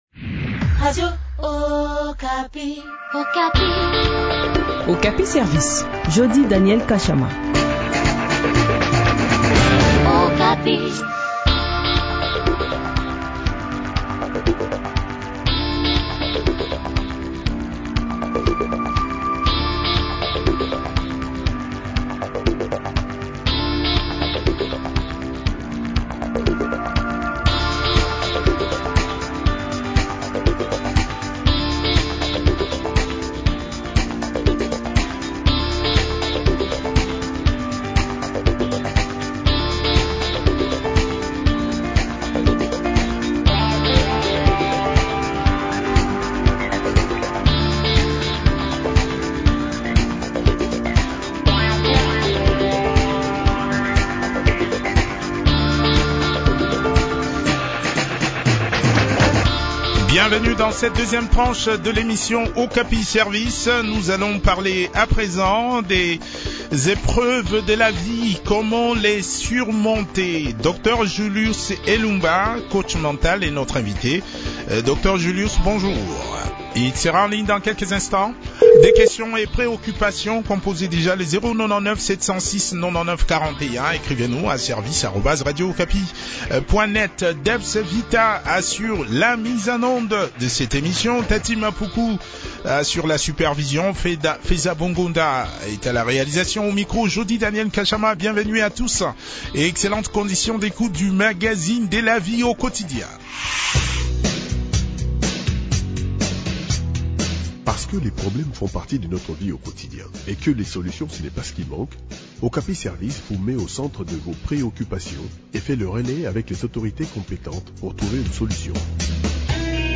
coach mental.